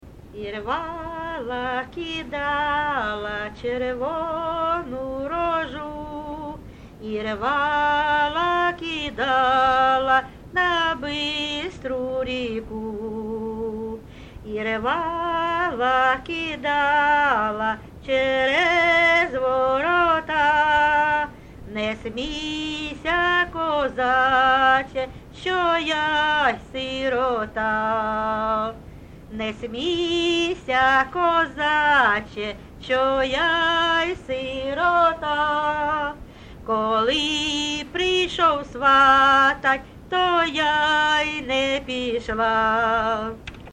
ЖанрПісні з особистого та родинного життя
Місце записус. Закітне, Краснолиманський (Краматорський) район, Донецька обл., Україна, Слобожанщина